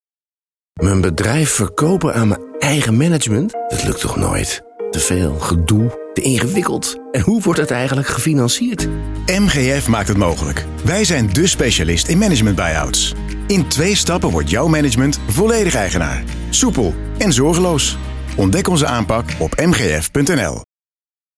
Heb je ons al op BNR Nieuwsradio gehoord?
In twee spots van 20 seconden vertellen we waar we bij MGF voor staan: een management buy-out zonder zorgen.